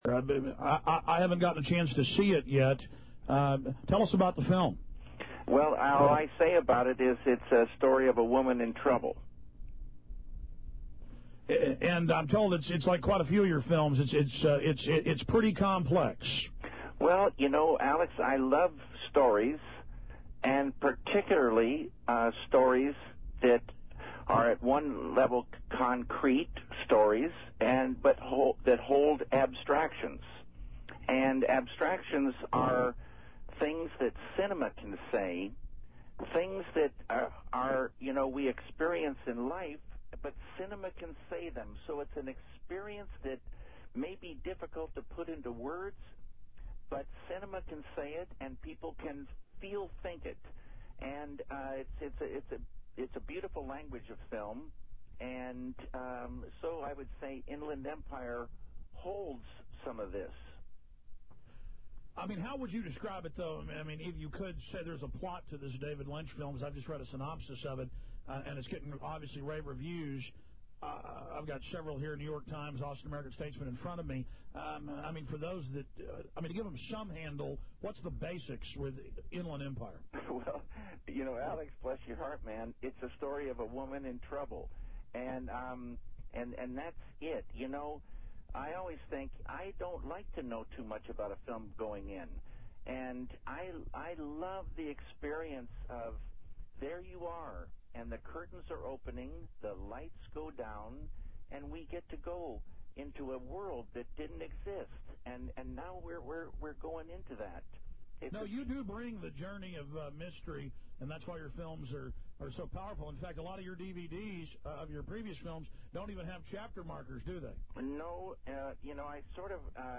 Film director David Lynch, in this 1/25/07 interview, speaks out on 9/11 .